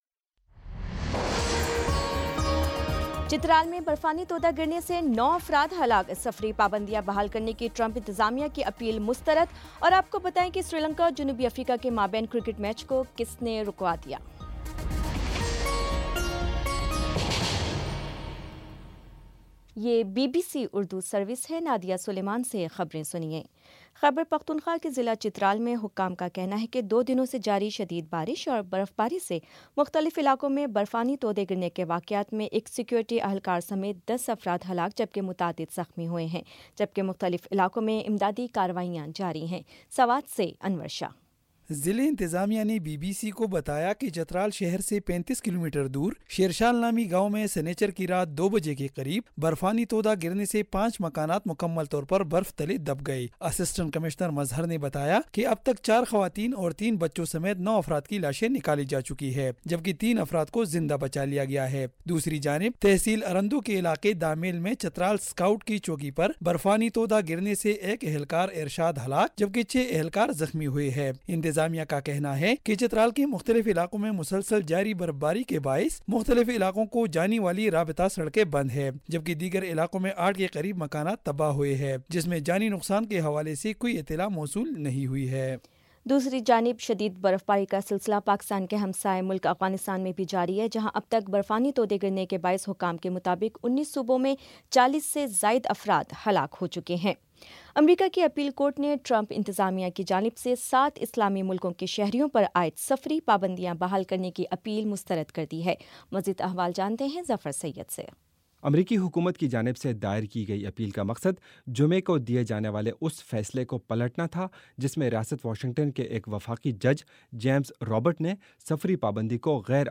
فروری 05 : شام چھ بجے کا نیوز بُلیٹن